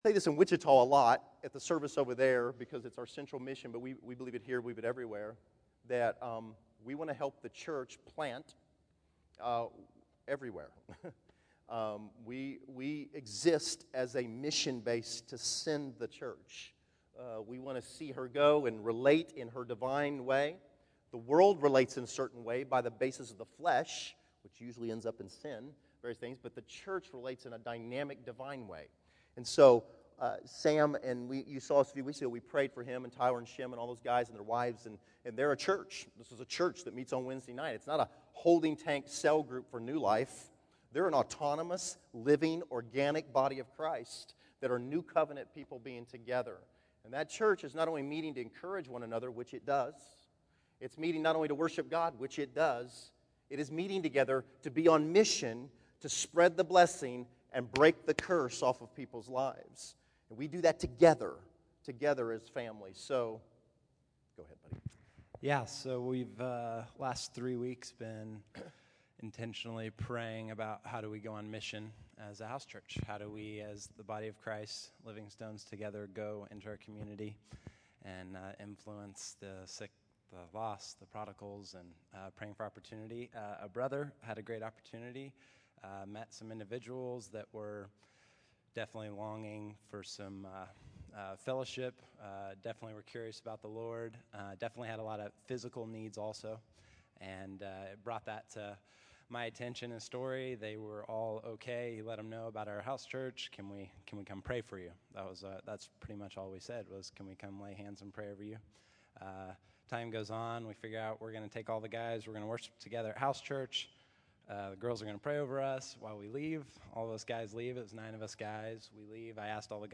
July 21, 2013      Category: Testimonies      |      Location: El Dorado